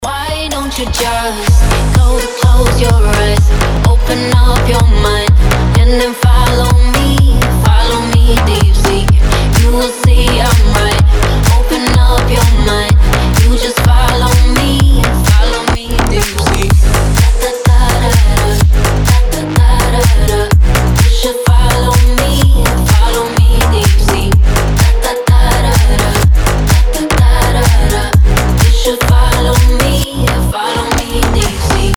Танцевальные рингтоны
Клубные рингтоны
клубняк
женский голос